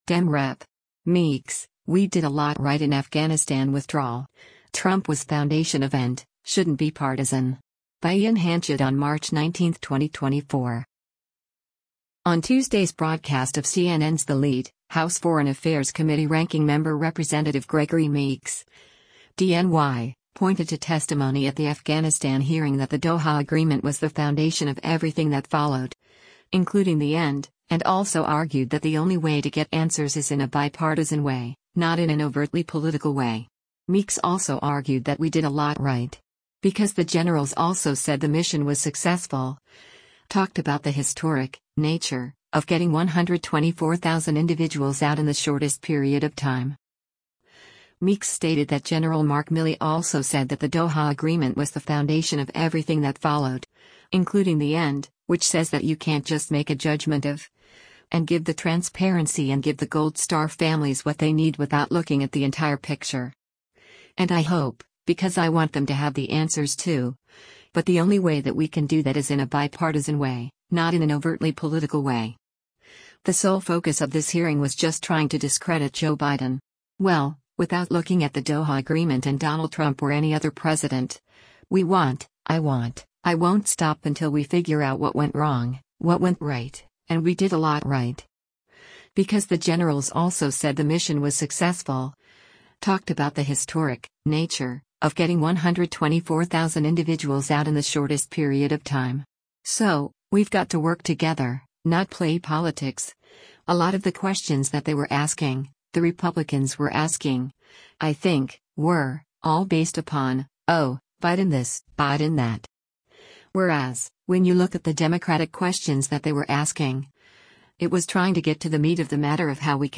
On Tuesday’s broadcast of CNN’s “The Lead,” House Foreign Affairs Committee Ranking Member Rep. Gregory Meeks (D-NY) pointed to testimony at the Afghanistan hearing “that the Doha Agreement was the foundation of everything that followed, including the end,” and also argued that the only way to get answers is “in a bipartisan way, not in an overtly political way.”